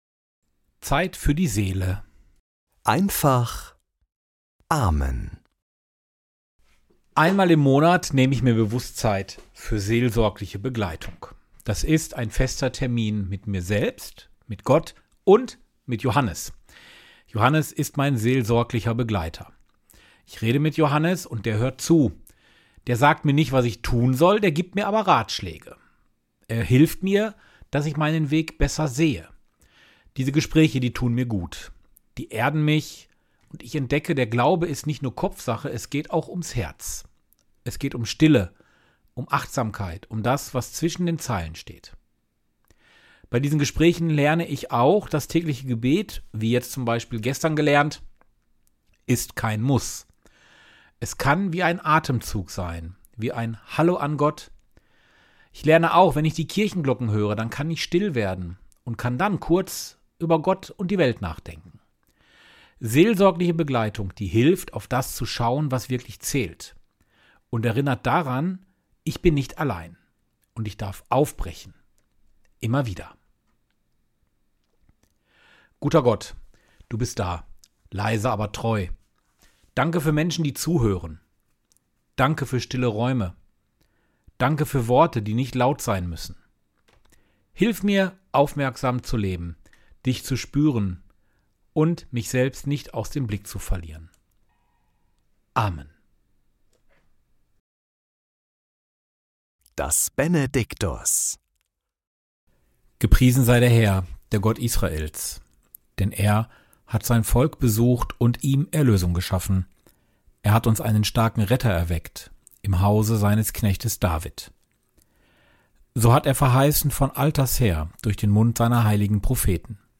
Morgenimpuls in Einfacher Sprache
Der Podcast mit Gebeten, Impulsen und Gedanken - in einfacher Sprache.